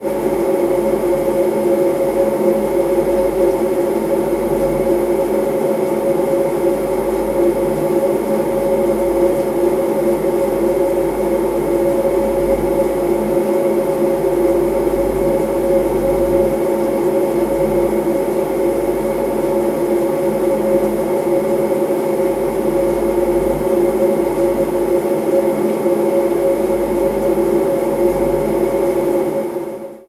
Motor de un frigorífico desde el interior
motor
nevera
Sonidos: Hogar